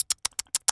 pgs/Assets/Audio/Animal_Impersonations/mouse_eating_01.wav at master
mouse_eating_01.wav